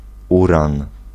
Ääntäminen
US
IPA : /jʊˈreɪniəm/